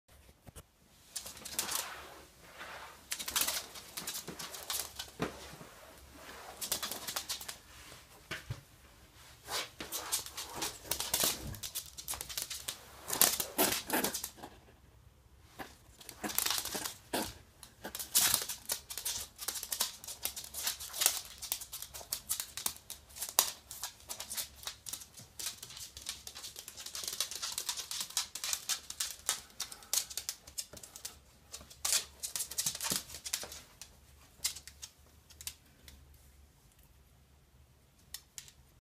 На этой странице собраны натуральные звуки ежей: фырканье, шуршание листьев и другие характерные шумы.
Топот ежика по ламинату: звук передвижения по жесткому полу